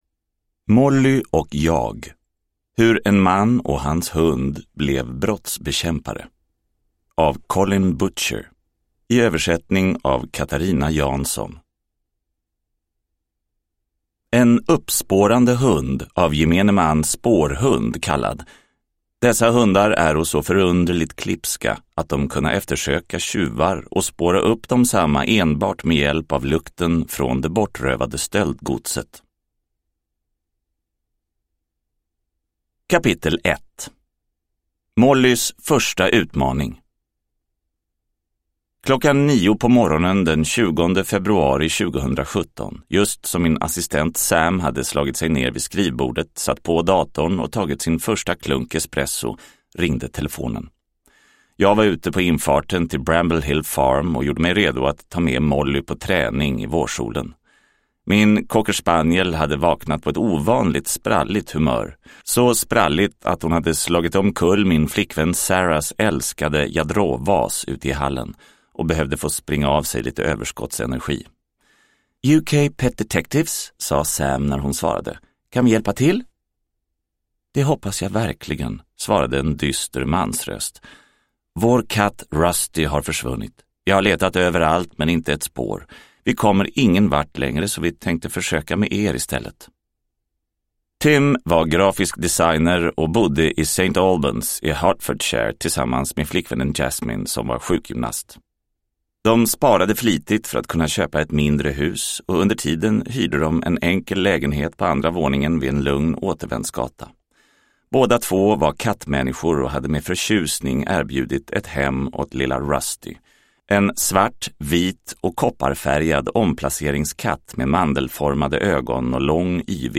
Molly och jag : hur en man och hans hund blev brottsbekämpare – Ljudbok – Laddas ner